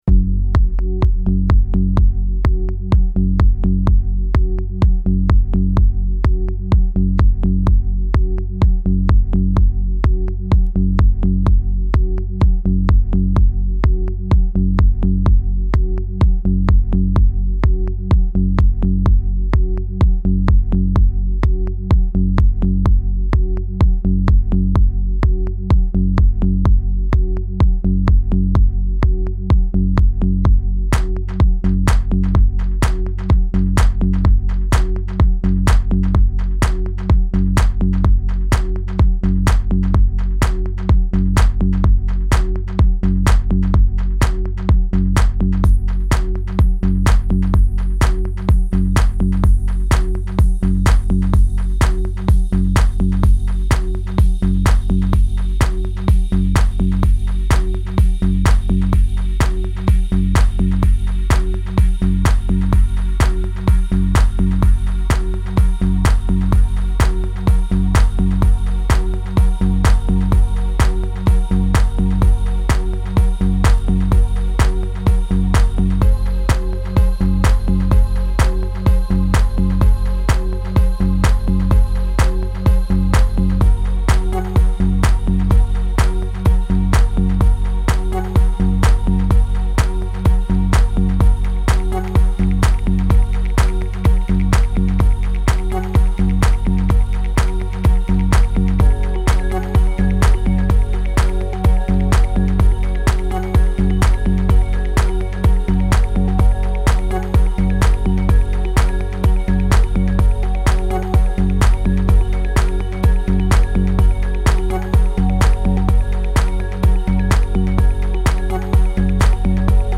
File under: Minimal/Techno/Neotrance.